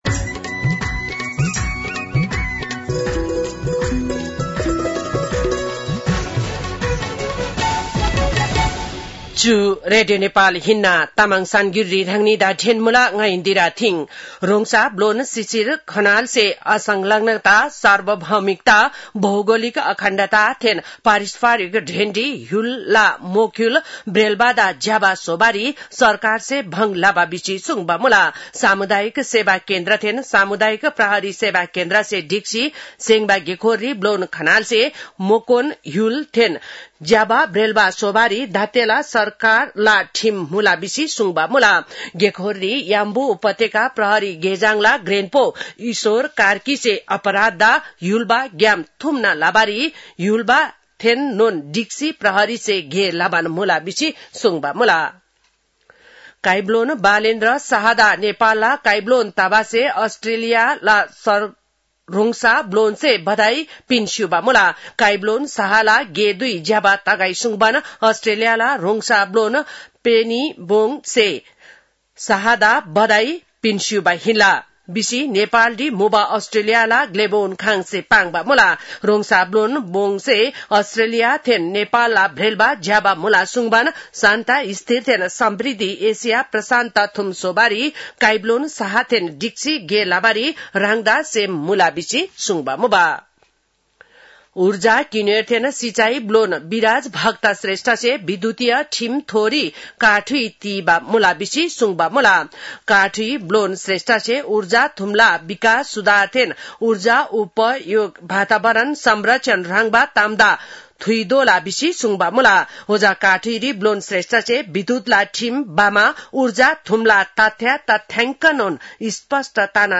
तामाङ भाषाको समाचार : २१ चैत , २०८२